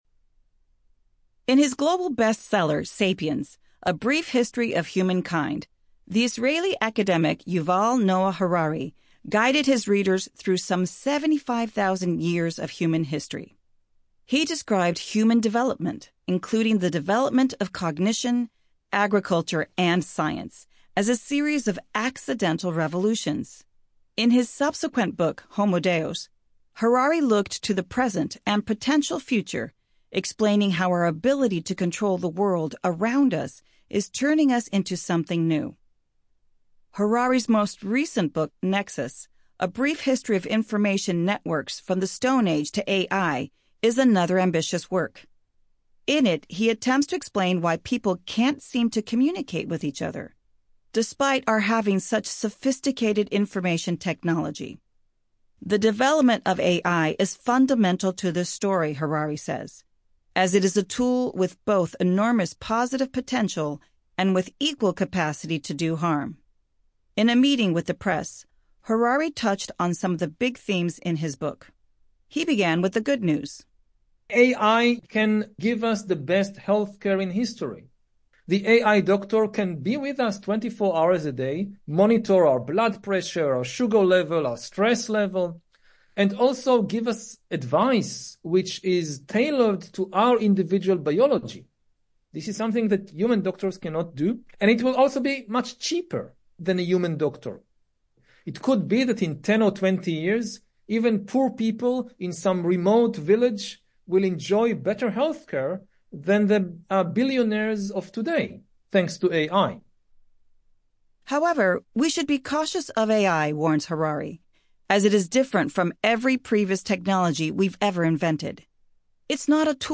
Current Affairs B2 Upper-intermediate
Yuval Noah Harari (Middle Eastern accent): AI can give us the best healthcare in history.